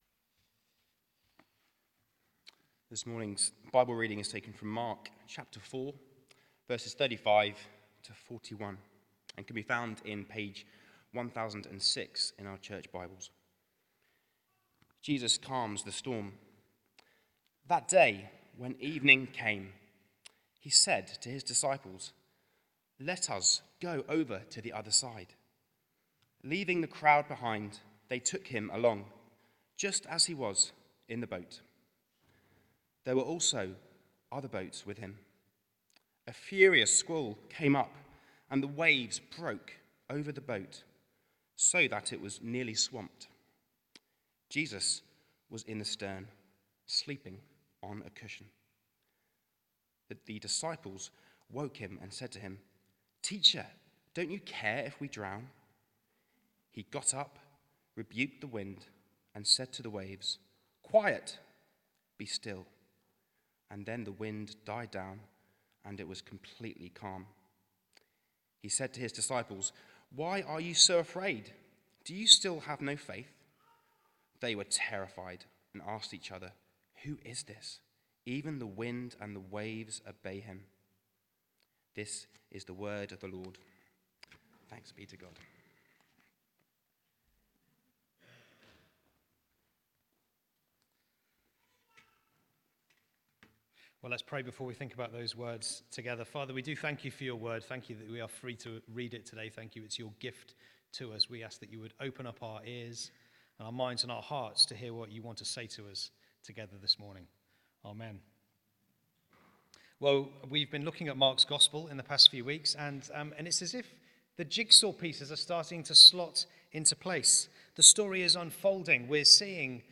Mark 4:35-41 Service Type: Sunday 11:00am Jesus Central